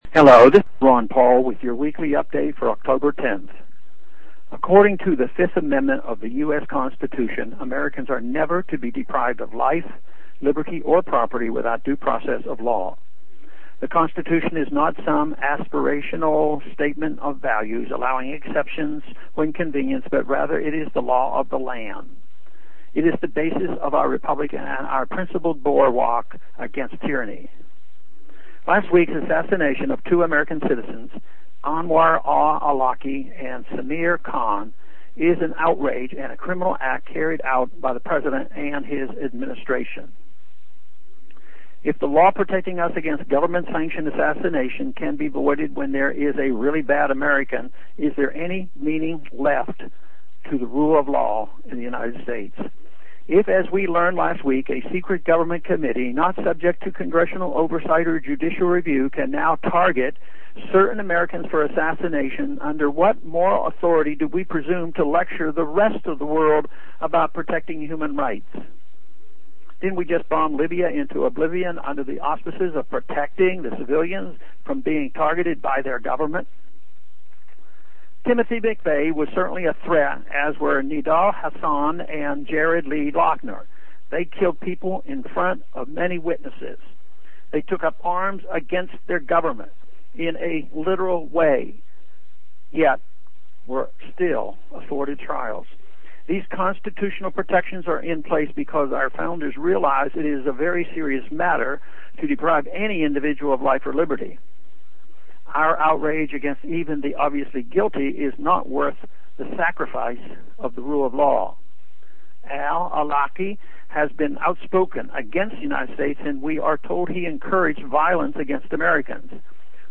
Listen to Rep. Ron Paul deliver this address.